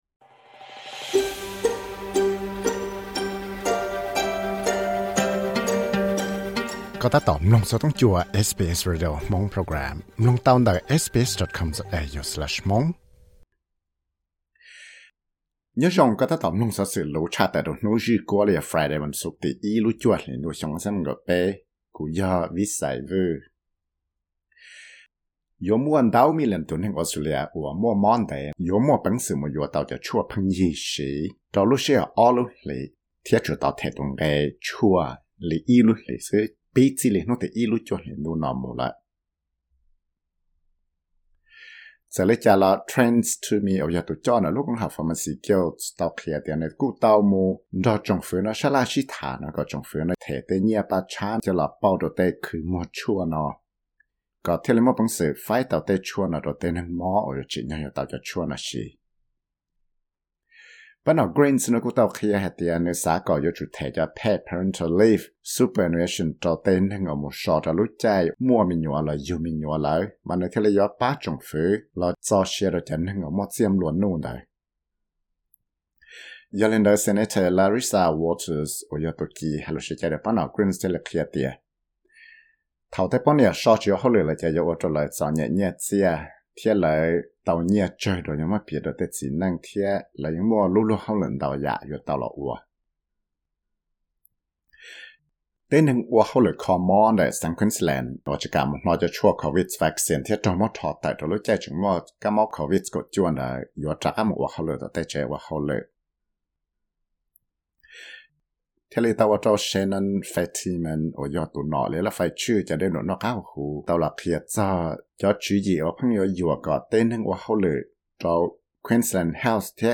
Credit: SBS studio